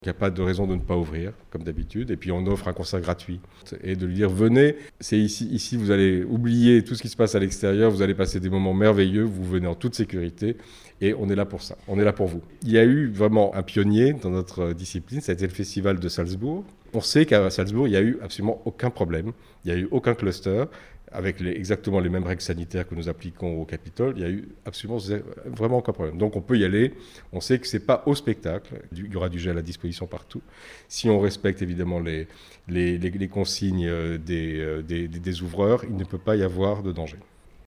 Reportage : à Toulouse, le théâtre du Capitole relance sa saison lyrique